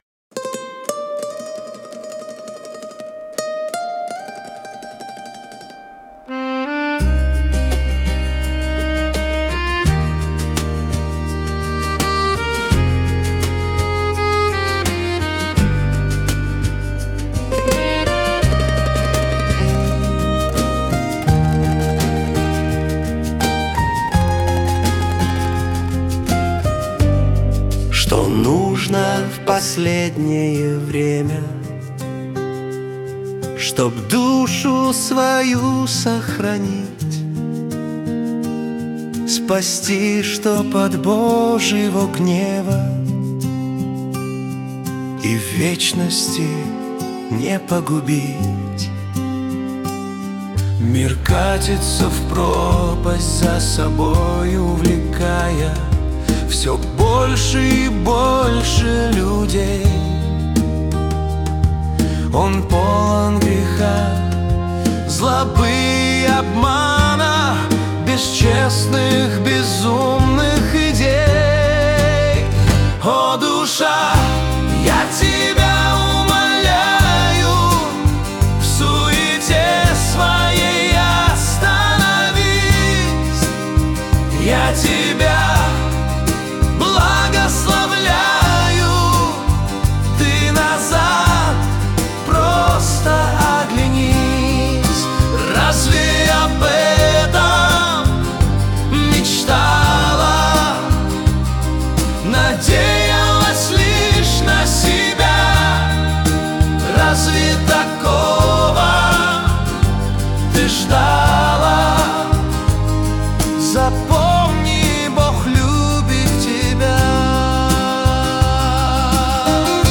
песня ai
115 просмотров 617 прослушиваний 44 скачивания BPM: 85